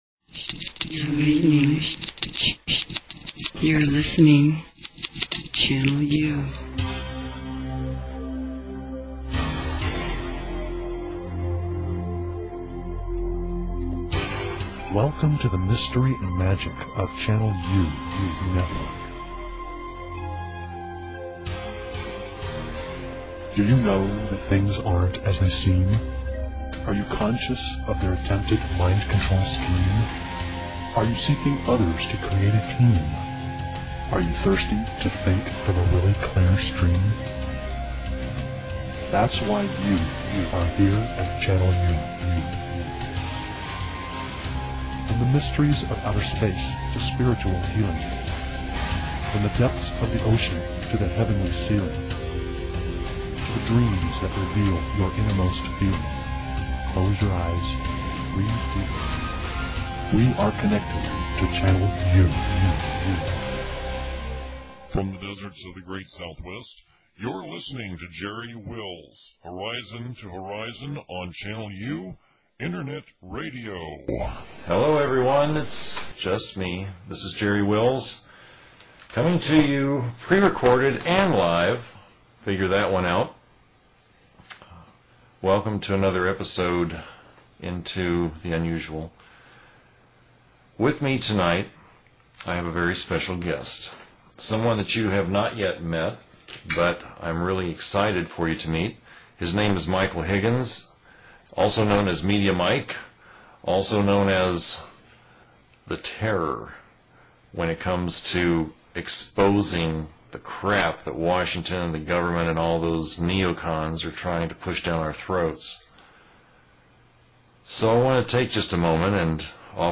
Talk Show Episode, Audio Podcast, Channel_U and Courtesy of BBS Radio on , show guests , about , categorized as